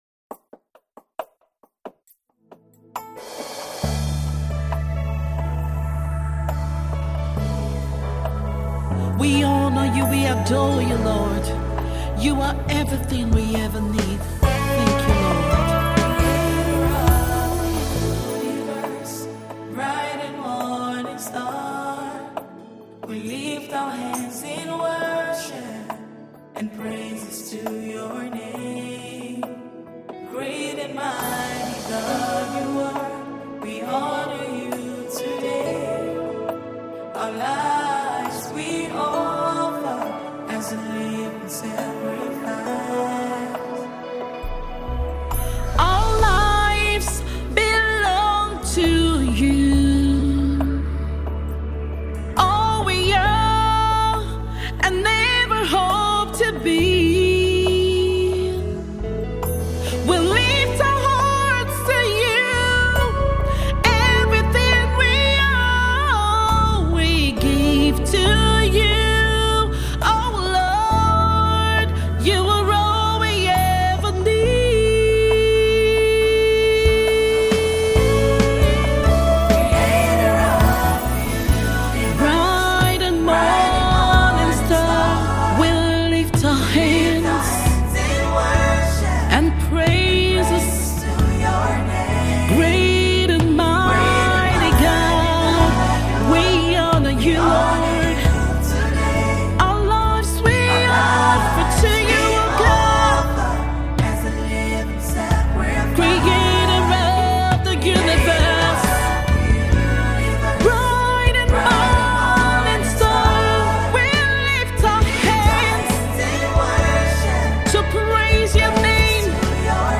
melodious
gospel
heart-melting single